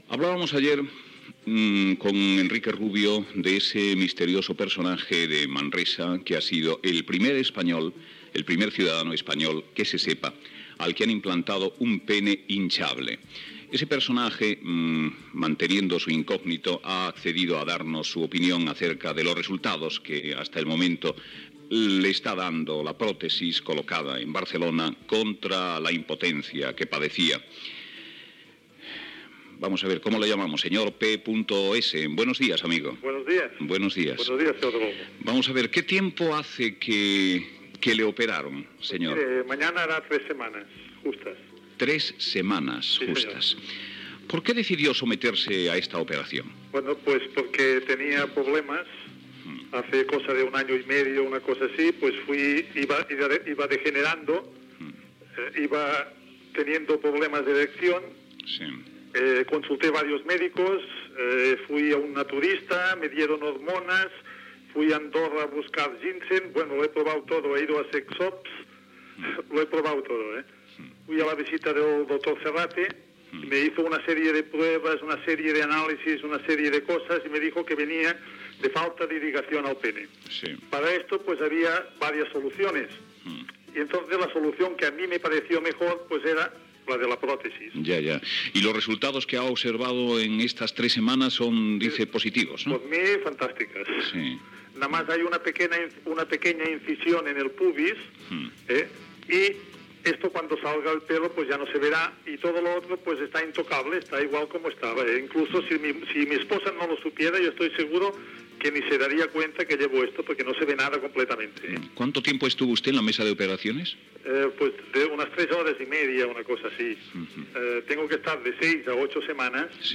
Fragment d'una entrevista a un pacient recentment operat de pròtesi infable del penis.
Info-entreteniment